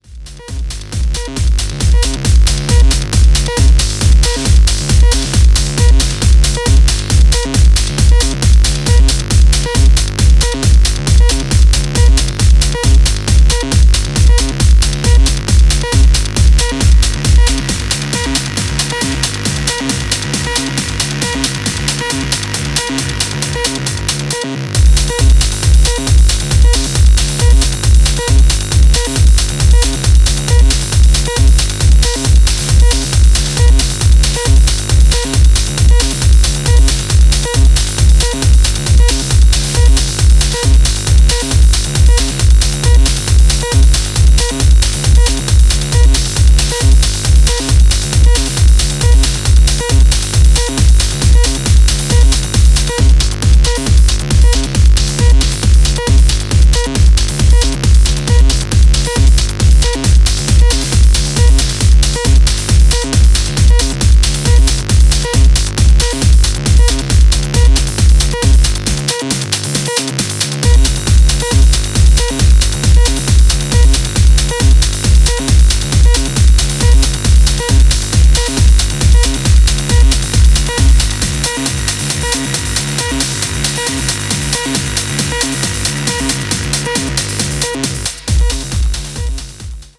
thundering jungle cut